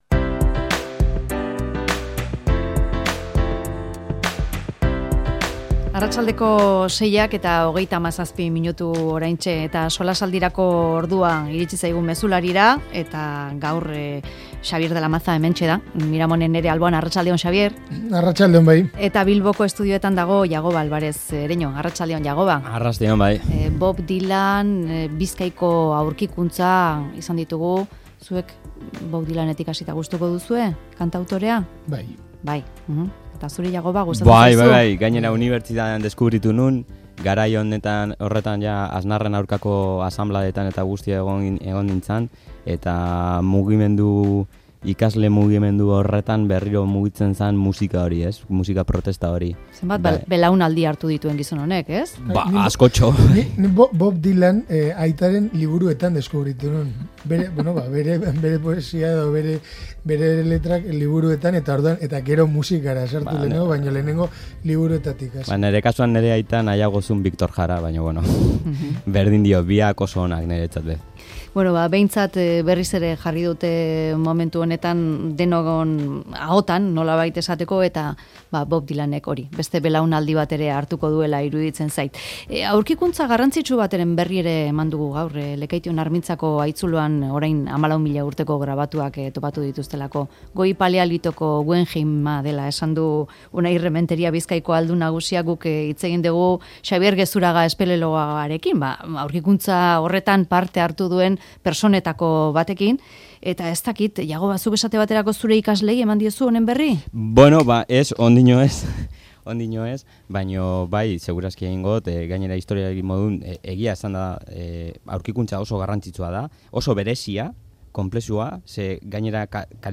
Mezularia|Tertulia